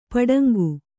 ♪ paḍaŋgu